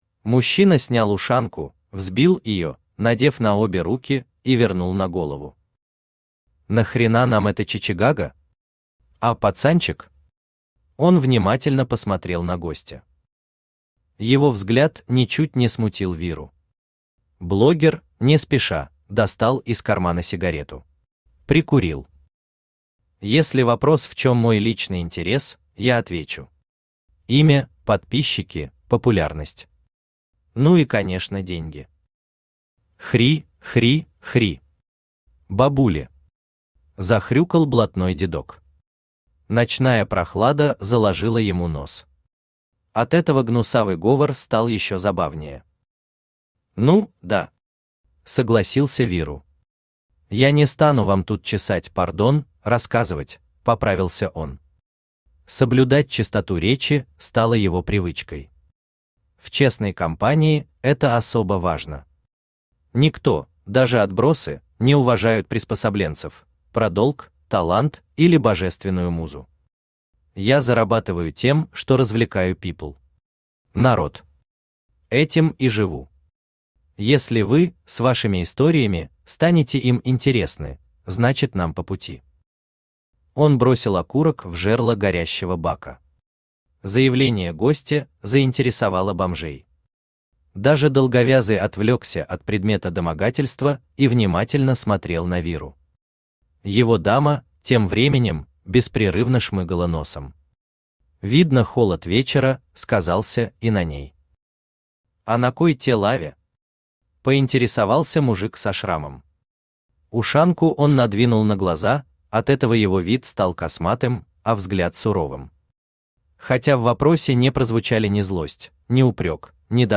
Аудиокнига Выход вниз. Филателист | Библиотека аудиокниг
Aудиокнига Выход вниз. Филателист Автор ШаМаШ БраМиН Читает аудиокнигу Авточтец ЛитРес.